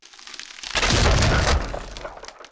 icefall3.wav